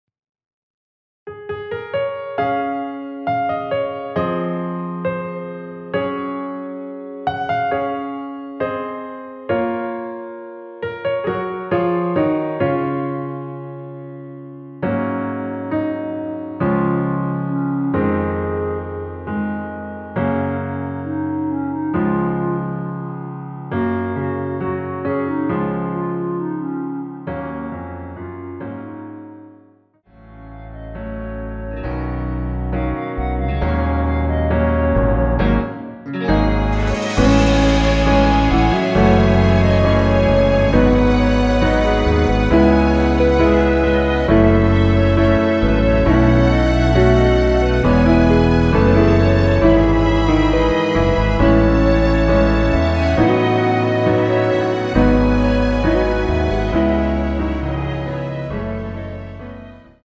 원키에서(-1)내린 멜로디 포함된 MR입니다.(미리듣기 확인)
Db
멜로디 MR이라고 합니다.
앞부분30초, 뒷부분30초씩 편집해서 올려 드리고 있습니다.
중간에 음이 끈어지고 다시 나오는 이유는